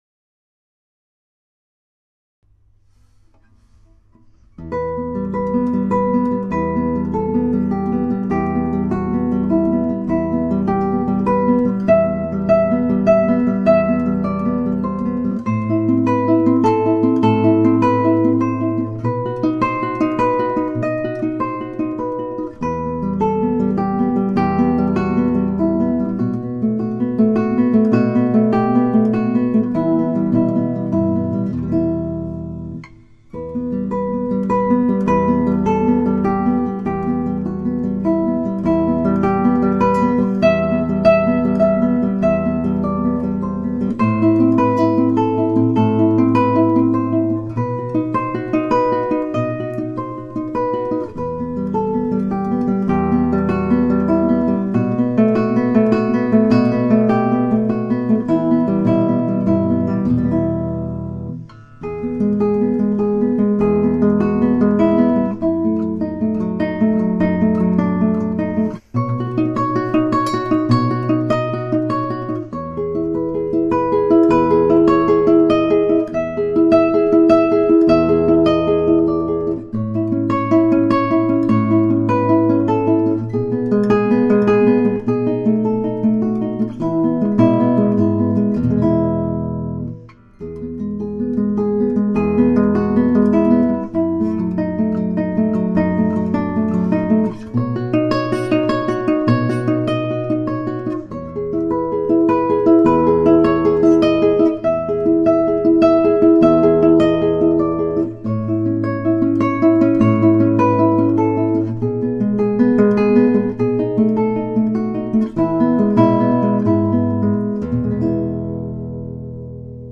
Le tempo demandé est de 100 à la noire.
L’événement est ouvert aux guitaristes de tous niveaux. 5 partitions du plus difficile au plus simple sont à votre disposition.
jeux_interdits_guitare_1.mp3